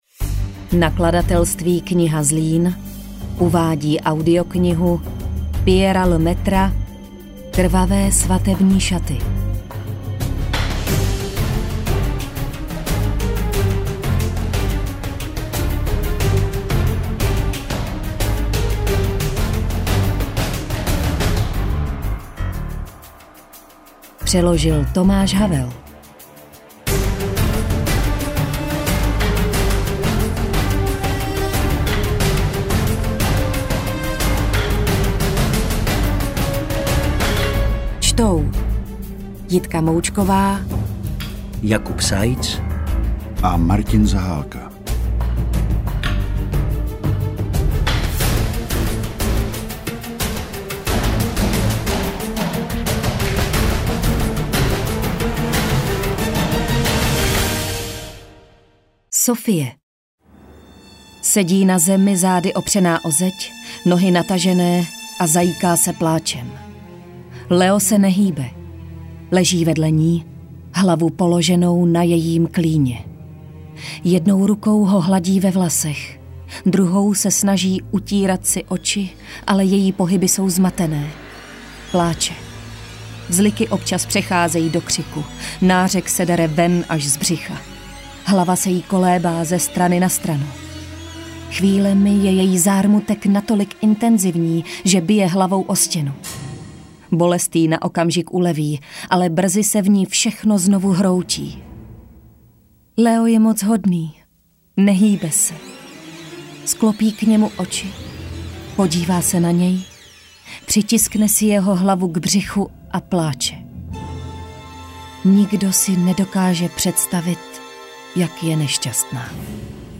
AudioKniha ke stažení, 45 x mp3, délka 9 hod. 53 min., velikost 539,3 MB, česky